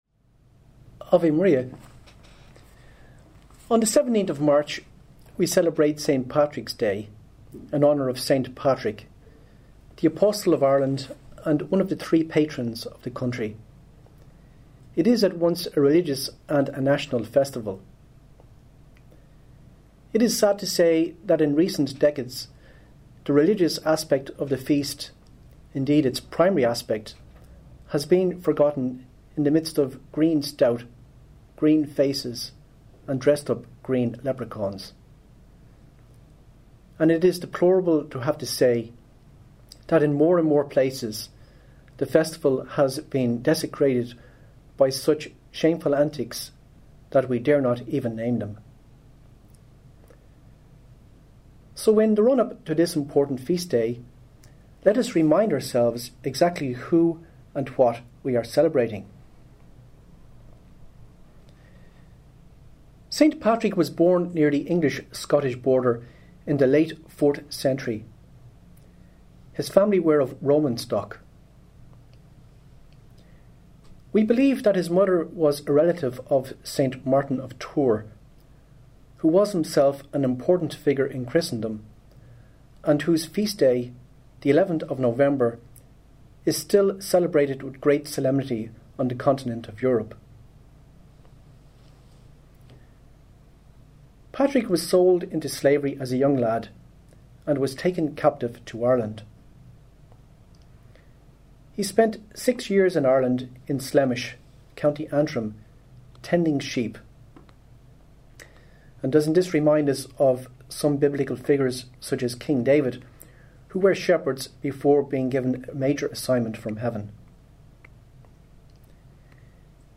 short talk